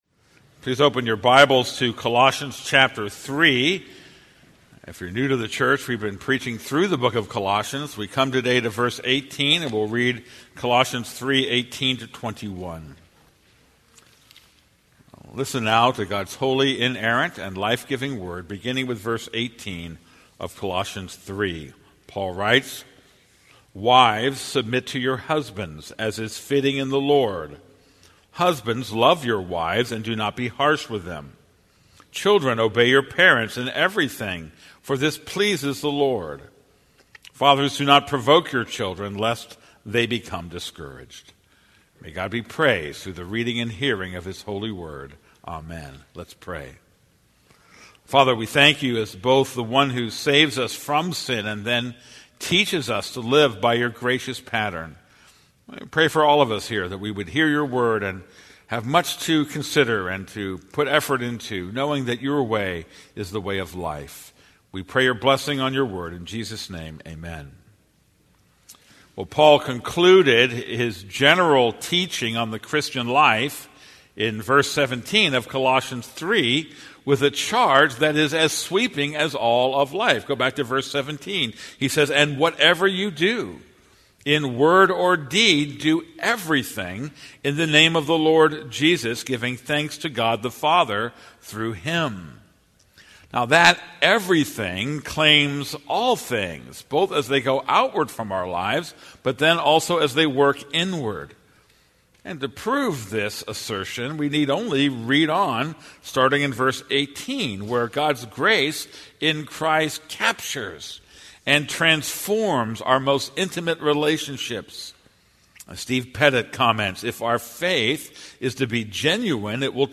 This is a sermon on Colossians 3:18-21.